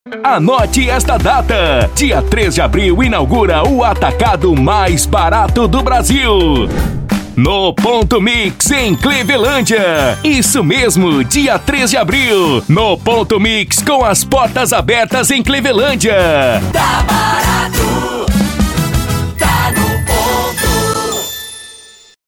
ESTILO PADRÃO SORRISO :